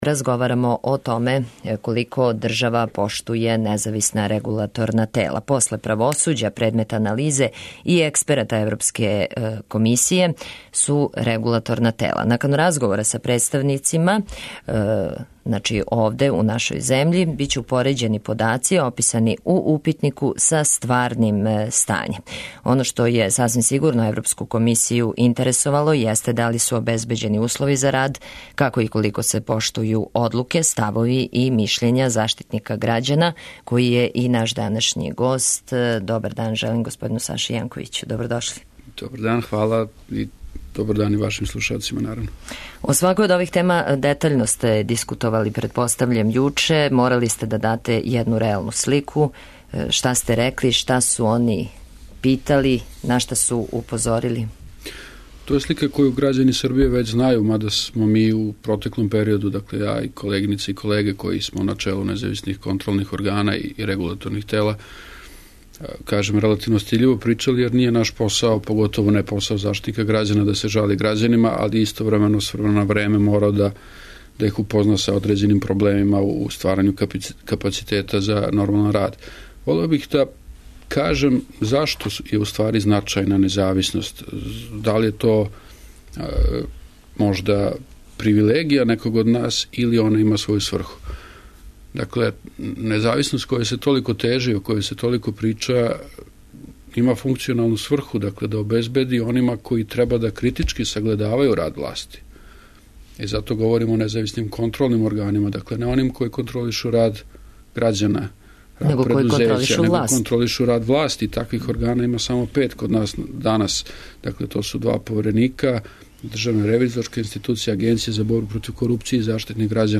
То су питања која отварамо у емисији, а наш гост је републички омбудсман Саша Јанковић који је разговарао са експертима Европске комисије о раду контролних и назависних регулаторних тела.